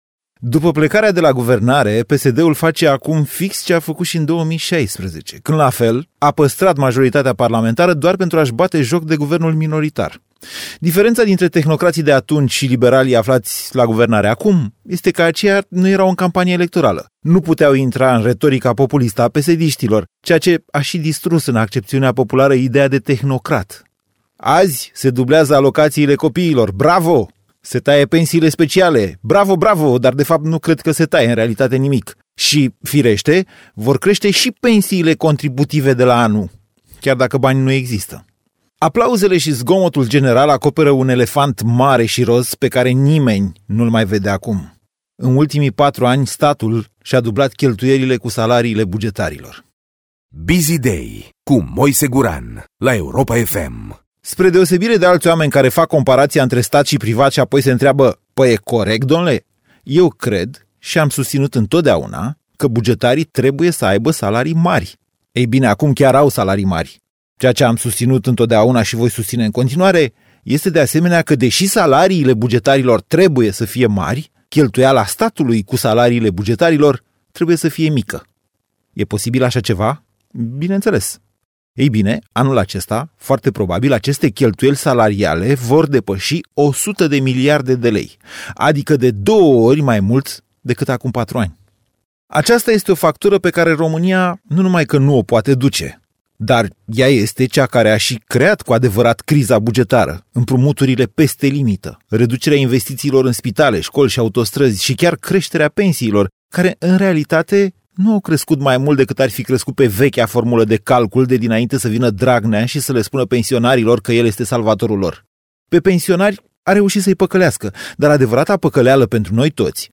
Pastila Biziday este adusă de Moise Guran la Europa FM în fiecare zi, la 7:30 şi 17:30.